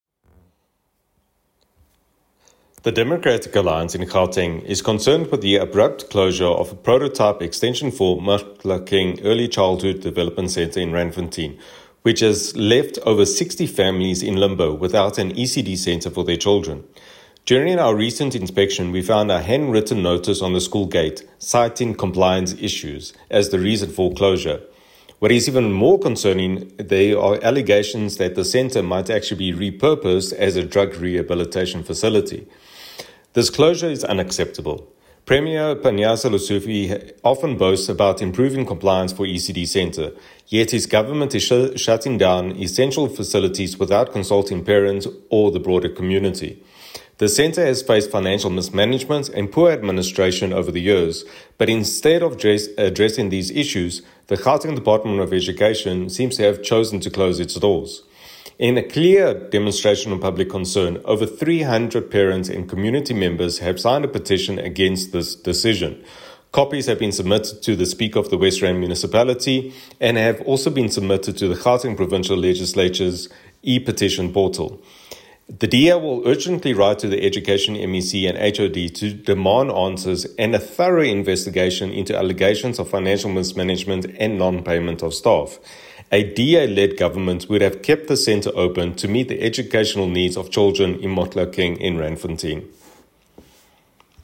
English soundbite by Sergio Isa Dos Santos MPL.